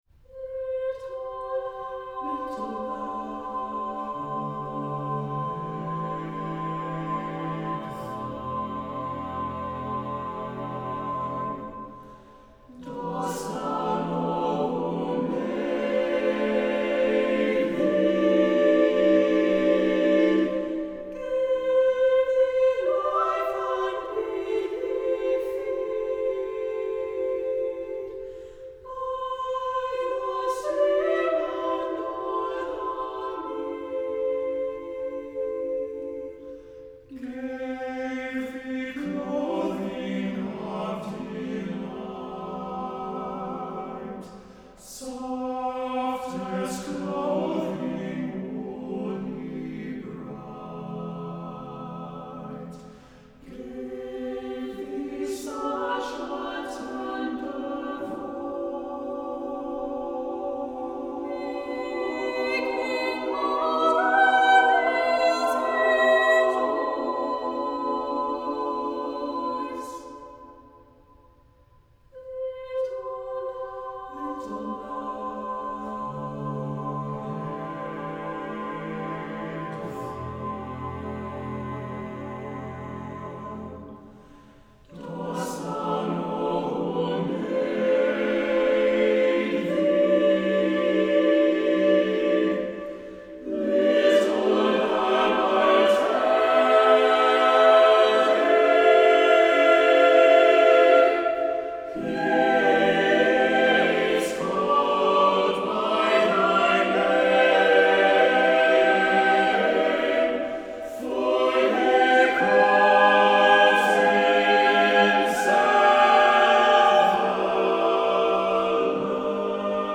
SATB a cappella chorus with divisi & Soprano Solo